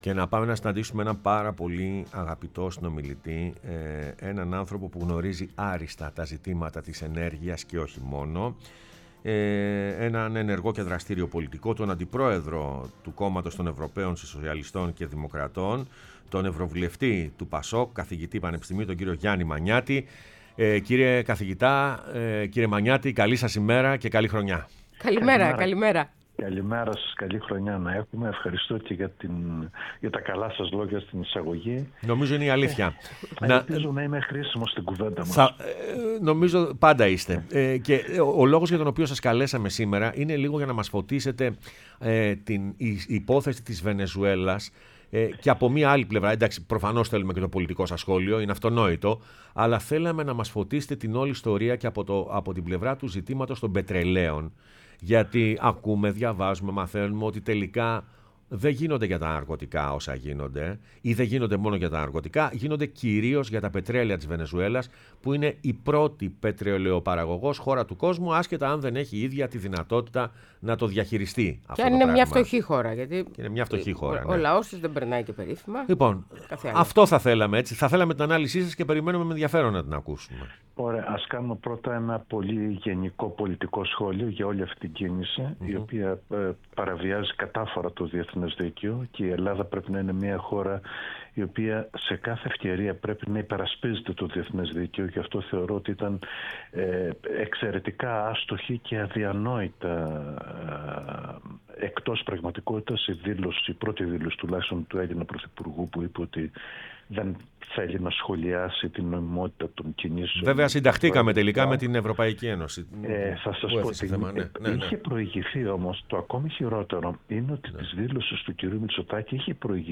Ο Γιάννης Μανιάτης, Ευρωβουλευτής ΠΑΣΟΚ – Αντιπρόεδρος Ευρωομάδας Σοσιαλιστών και Δημοκρατών – Καθηγητής Πανεπιστημίου Πειραιά, μίλησε στην εκπομπή “Πρωινές Διαδρομές”